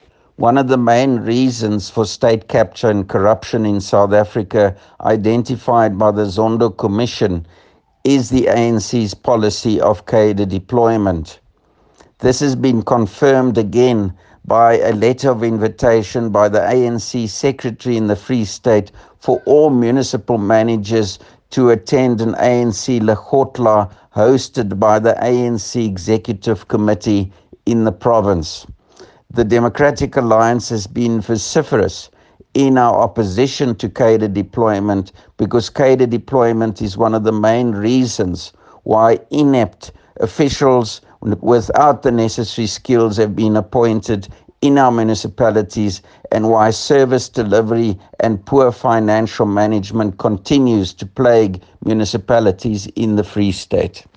English and Afrikaans soundbites by Dr Roy Jankielsohn MPL